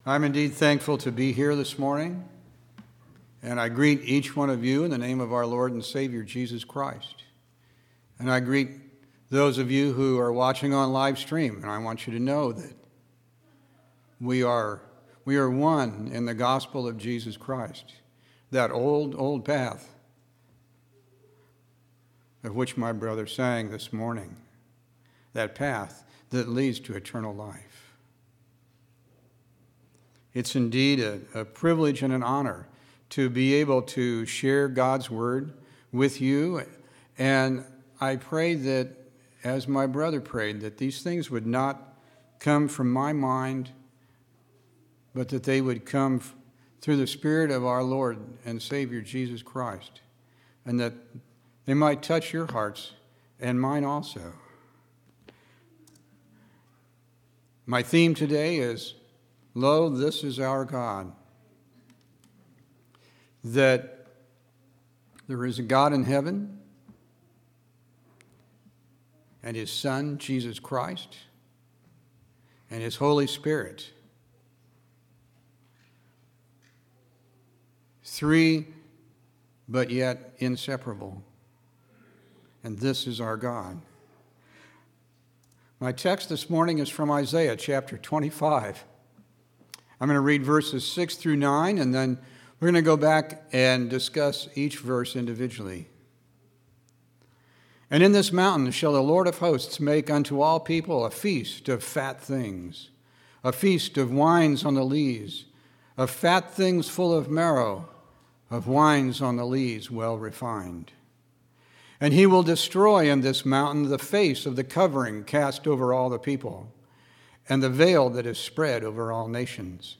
9/14/2014 Location: Temple Lot Local Event